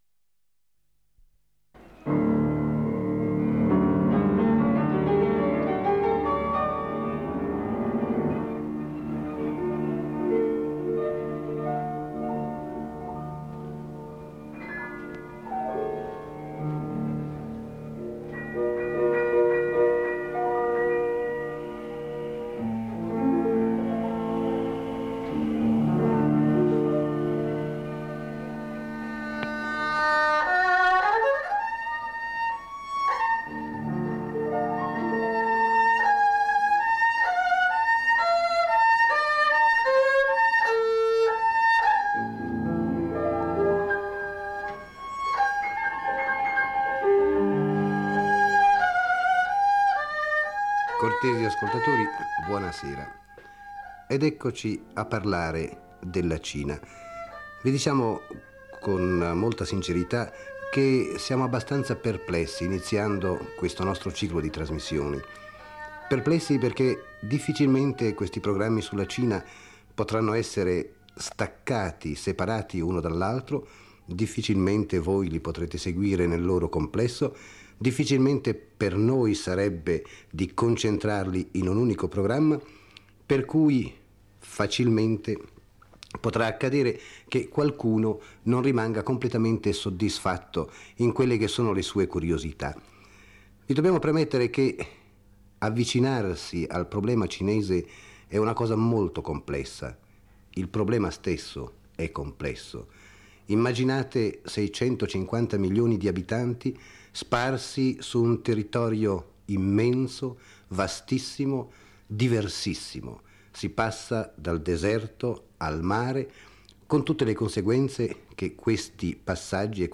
In questa prima tappa, il viaggio conduce al Conservatorio nazionale di Pechino, dove si svolge una mattinata musicale: alcuni studenti si esibiscono con strumenti tradizionali, come il pipa e l’erhu, offrendo un primo assaggio della ricca cultura musicale cinese.